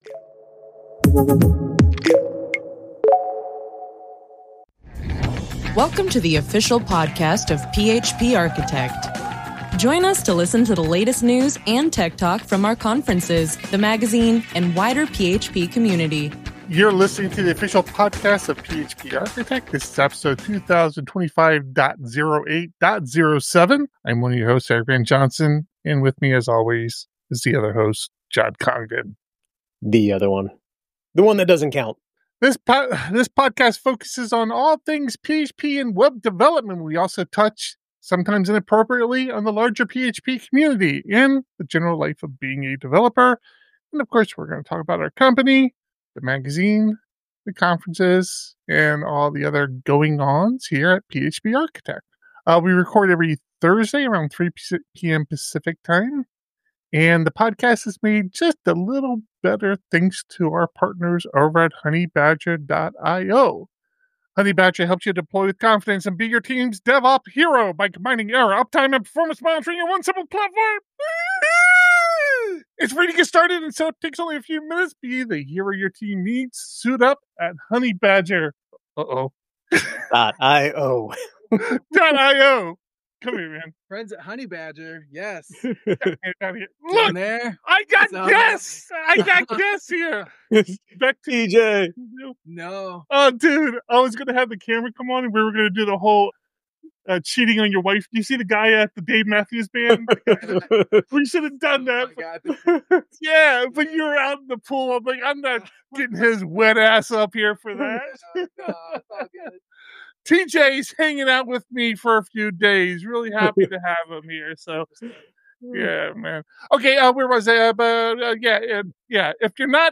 The PHP Podcast streams the recording of this podcast live, typically every Thursday at 3 PM PT.